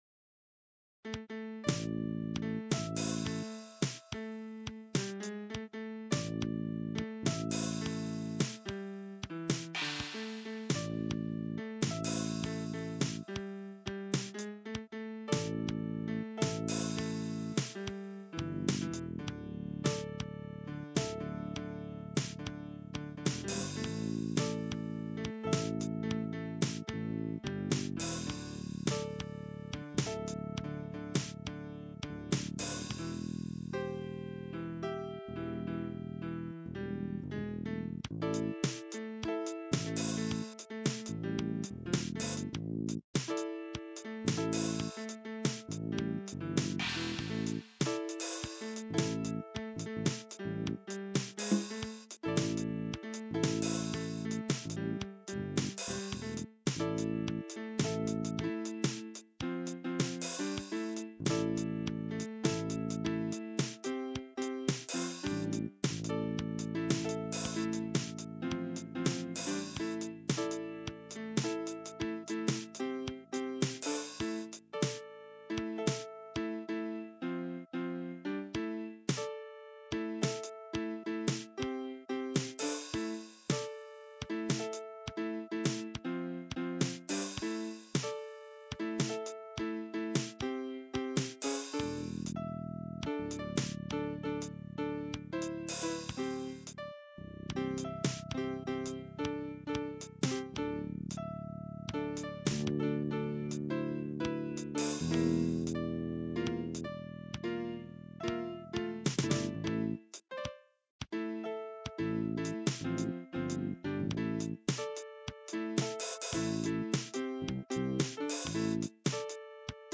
Rythym Drums with simple melody.